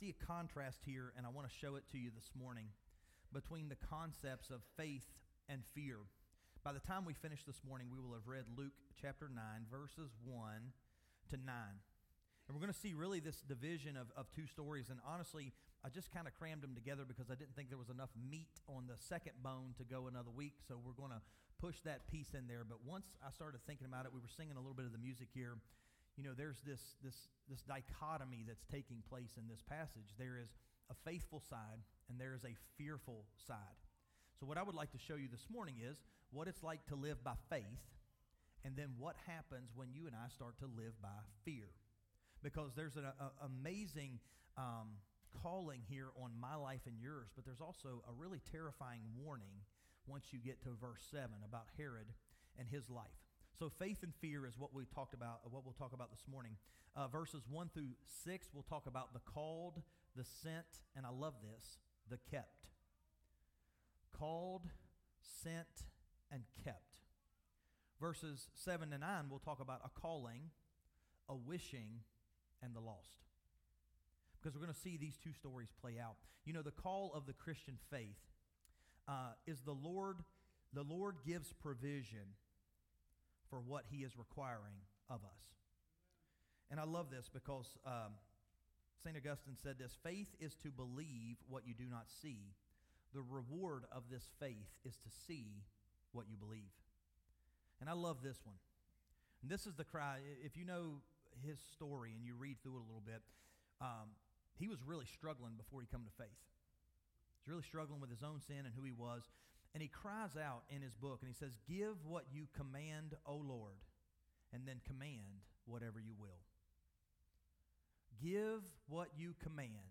Sermons by HBCWV